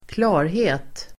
Uttal: [²kl'a:rhe:t]